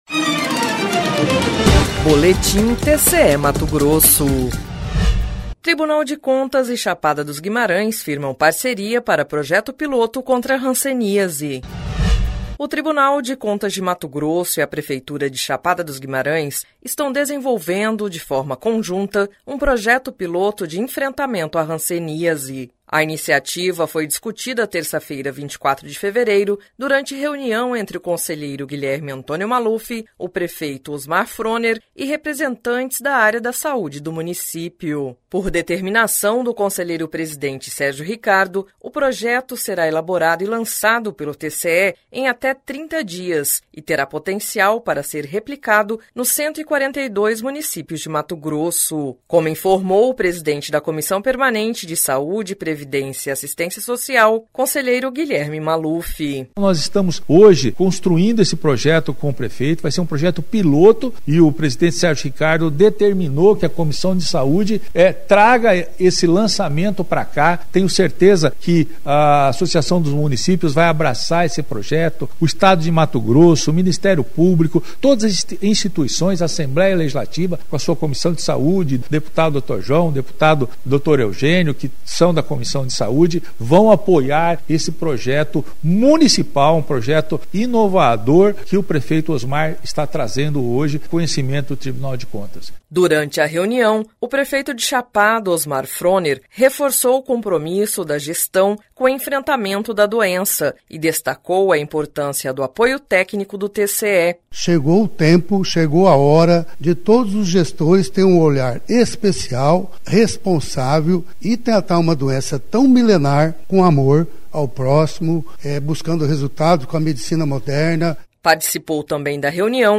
Sonora: Guilherme Antonio Maluf – conselheiro presidente da COPSPAS do TCE-MT
Sonora: Osmar Froner - prefeito de Chapada do Guimarães